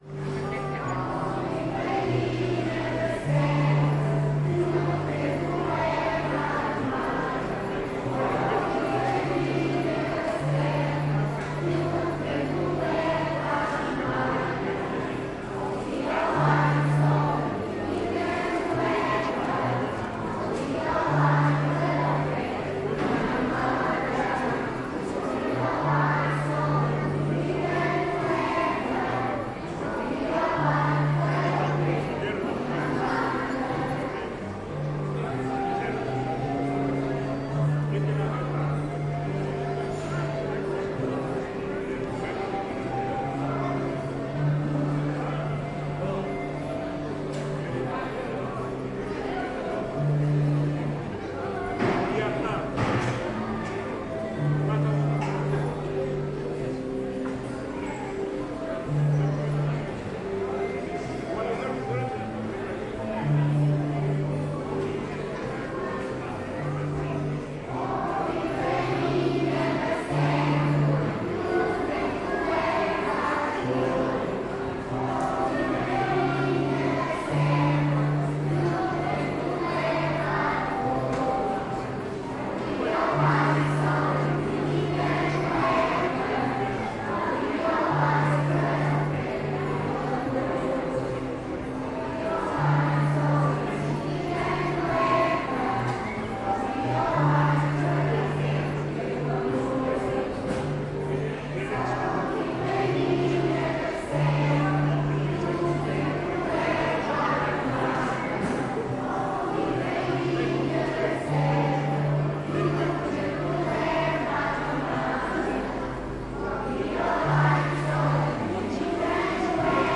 描述：来自拉贾斯坦邦的传统音乐。
Tag: 唱歌 氛围 拉贾斯坦邦 斋沙默尔 音乐 打击乐 传统 印度 乐器 舞蹈 歌曲 现场记录